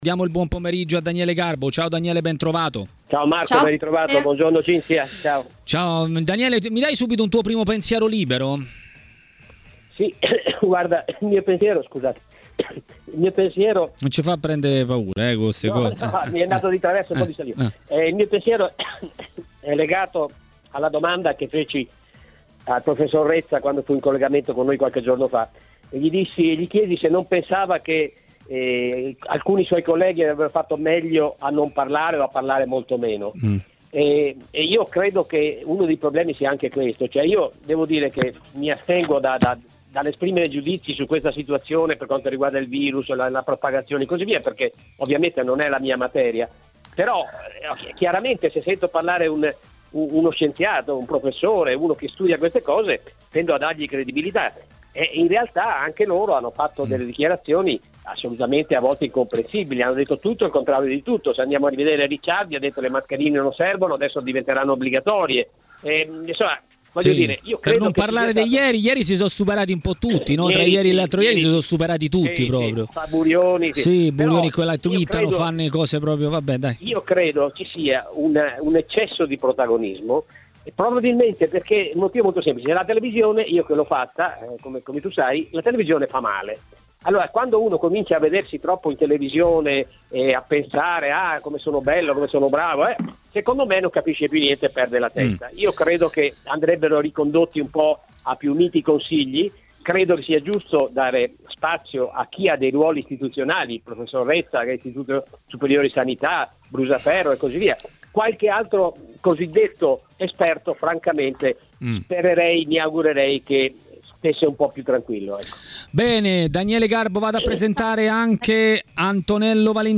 a Maracanà, nel pomeriggio di TMW Radio, ha parlato della possibile ripresa dei campionati dopo l'emergenza Covid-19.